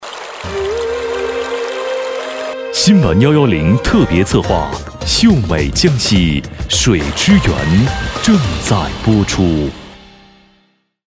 男声配音
新闻男国207